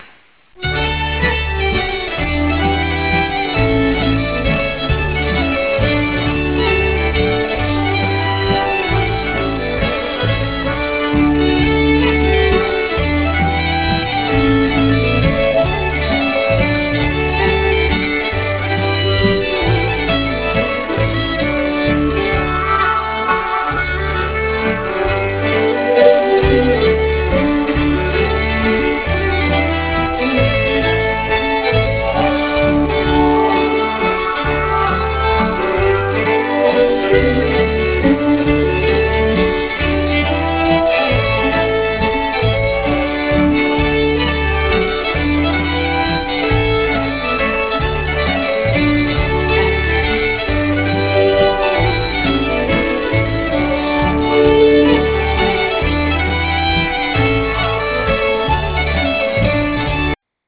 Pols